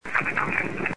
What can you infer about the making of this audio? All are Class C to B quality voices. The following have been amplified and spikes from the recorder have been reduced: